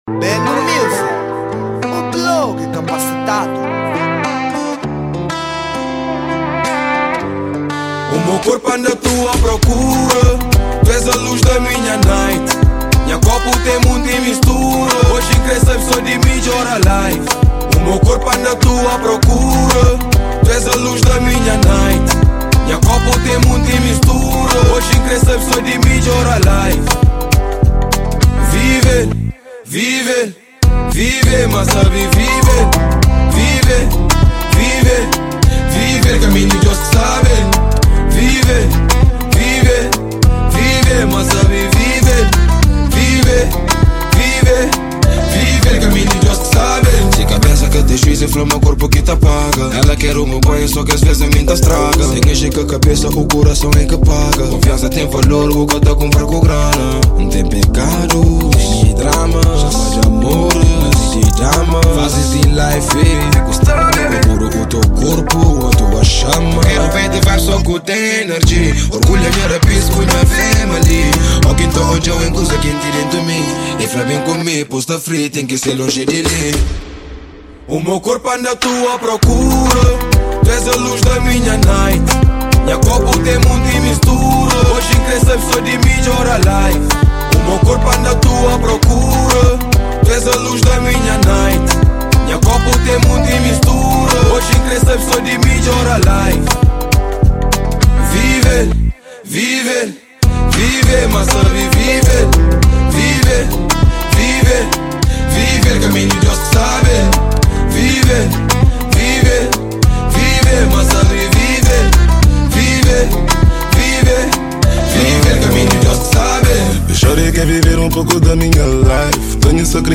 Género : Kizomba